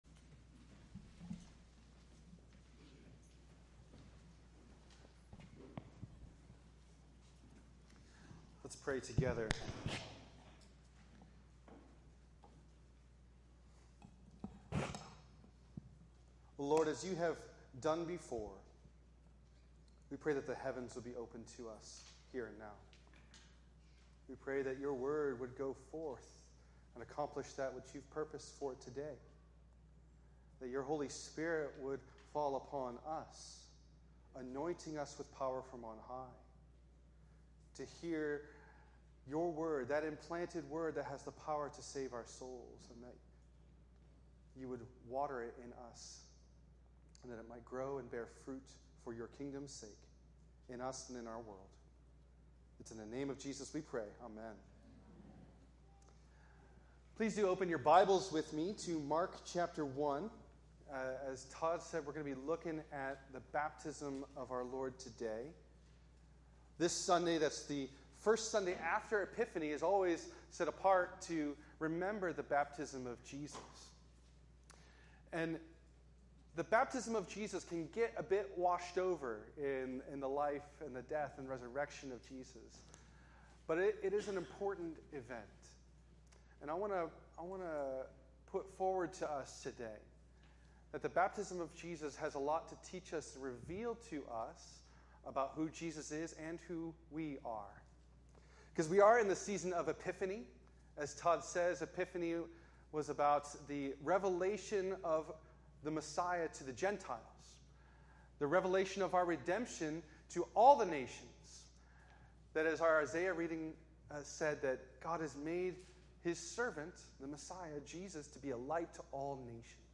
preaches on the baptism of our Lord, and our baptism.